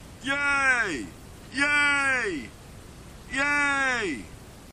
YAY
aliens car cheer computer fart flatulation flatulence gas sound effect free sound royalty free Memes